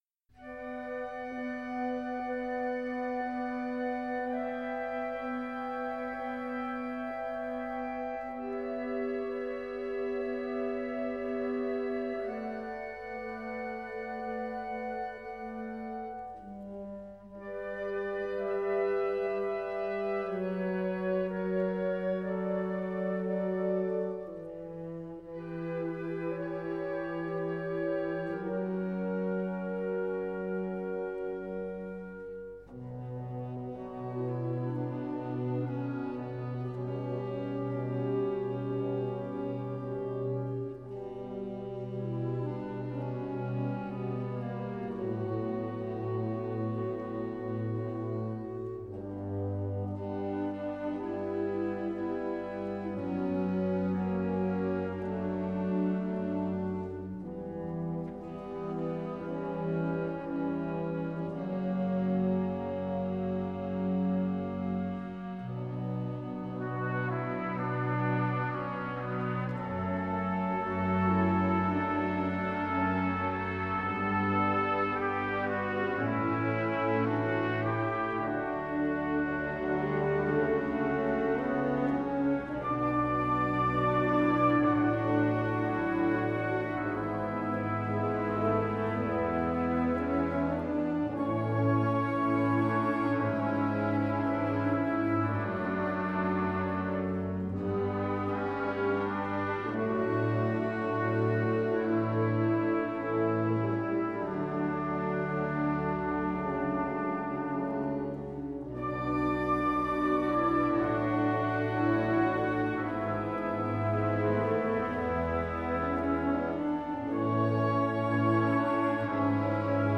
Bladmuziek voor harmonie.